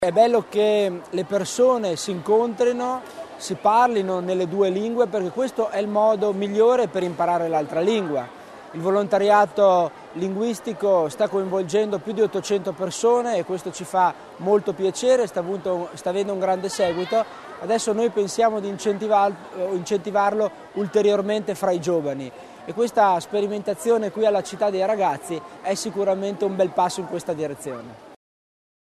L'Assessore Tommasini sul valore del progetto dedicato ai giovani